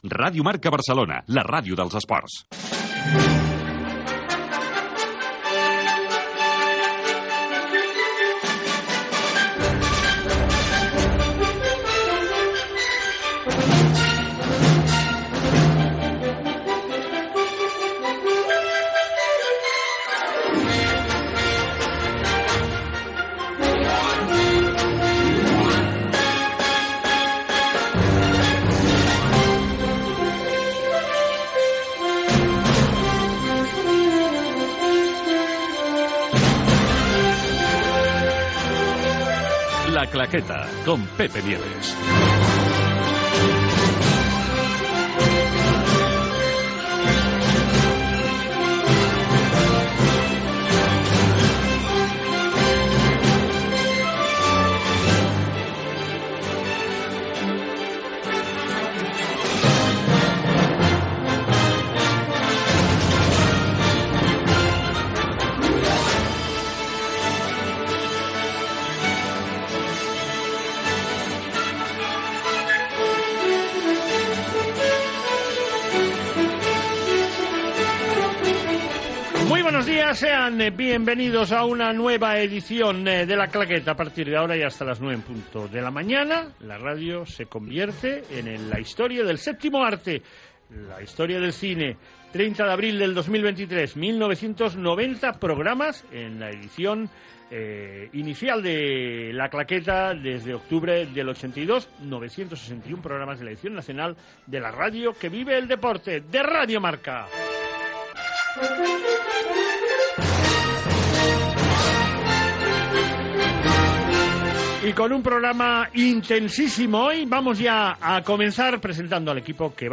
Indicatiu de l'emissora, careta del programa